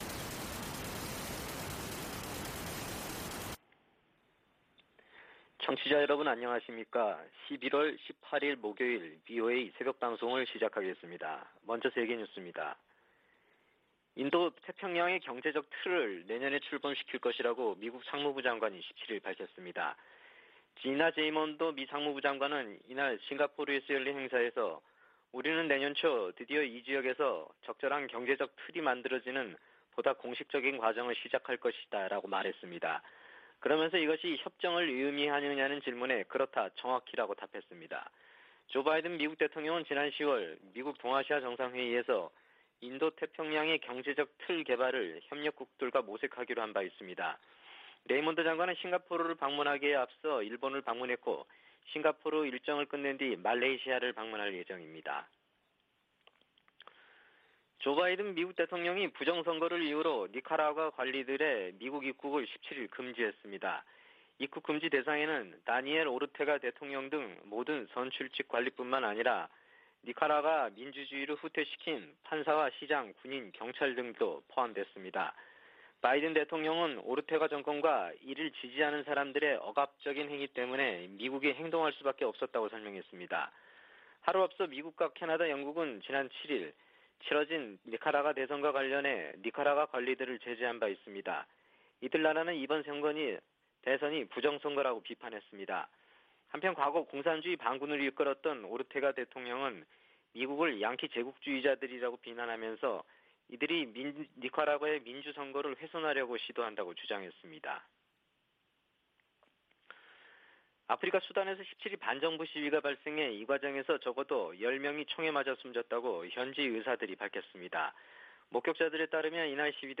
VOA 한국어 '출발 뉴스 쇼', 2021년 11월 18일 방송입니다. 미국의 백악관 국가안보보좌관은 북한 문제는 이란 핵과 함께 당면한 도전과제로 중국과의 긴밀한 조율이 중요하다고 밝혔습니다. 종전선언을 놓고 미-한 간 문안 합의가 조만간 이뤄질 듯한 한국 정부 당국자들의 발언이 이어지면서 성사 여부에 관심이 모아지고 있습니다.